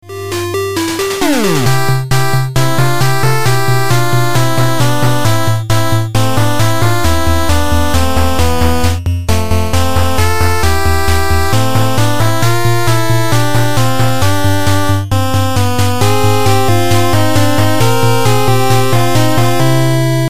8 бит
Отличного качества, без посторонних шумов.